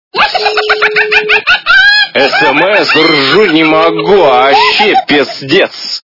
» Звуки » звуки для СМС » СМС-ка - Ржу, не могу!
При прослушивании СМС-ка - Ржу, не могу! качество понижено и присутствуют гудки.